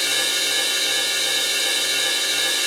normal-sliderslide.wav